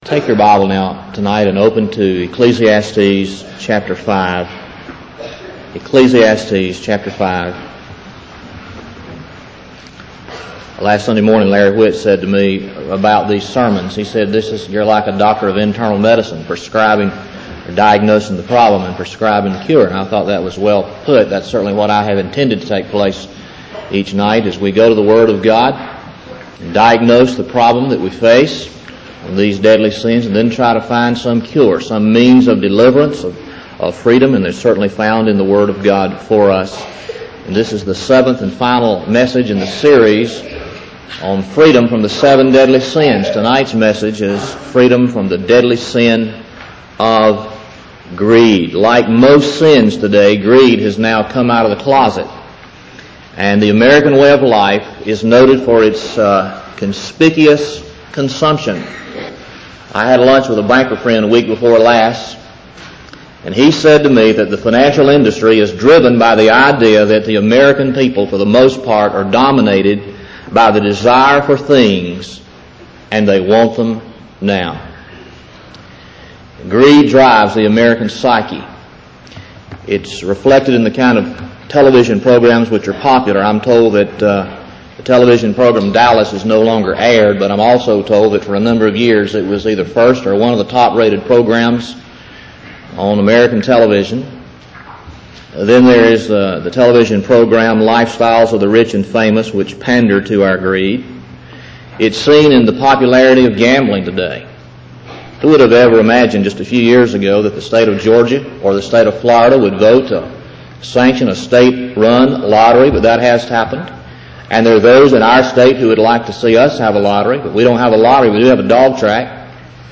Lakeview Baptist Church - Auburn, Alabama
Sermon